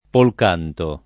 [ polk # nto ]